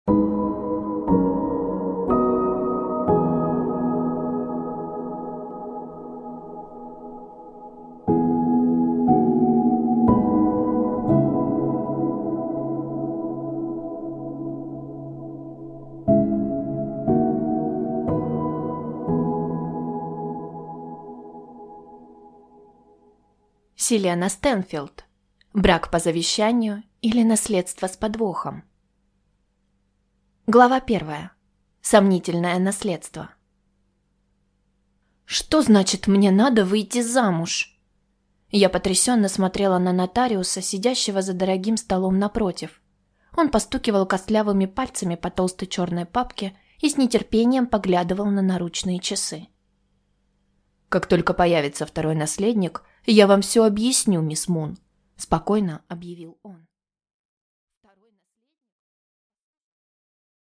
Аудиокнига Брак по завещанию, или Невеста с подвохом | Библиотека аудиокниг